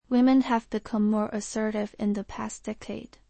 首先，我们进一步升级了发音系统，使中英发音尽可能接近真人。
在我们选取的发音中，不乏一些明星语音，小伙伴们可以猜猜她是谁？